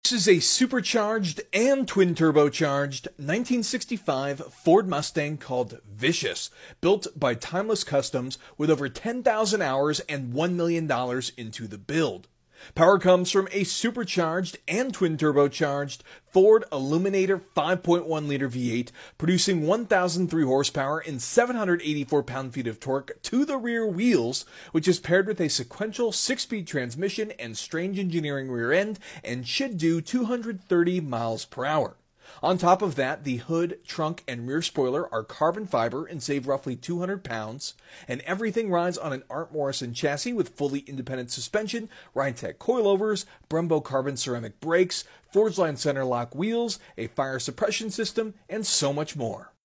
Supercharged AND Twin Turbocharged 1965 sound effects free download
Supercharged AND Twin Turbocharged 1965 Ford Mustang